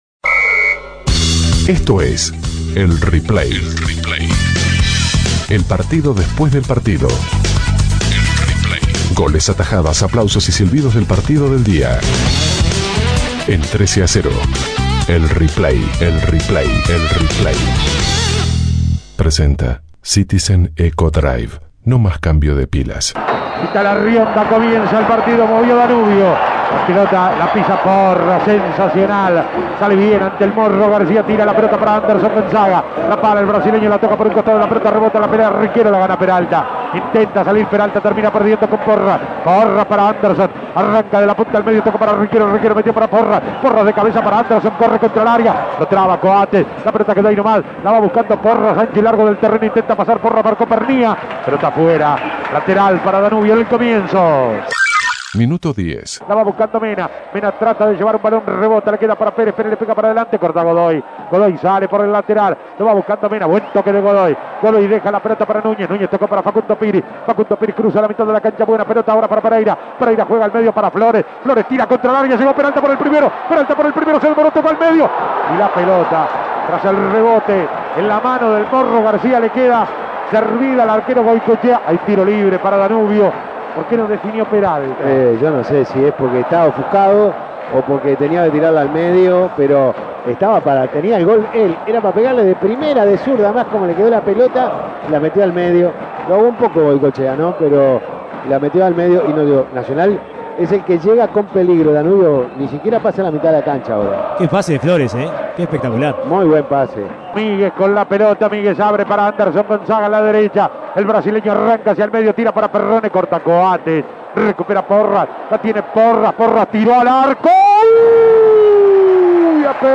Goles y comentarios Escuche el replay de Nacional - Danubio Imprimir A- A A+ Nacional le ganó por 2 a 1 a Danubio en el Estadio Centenario.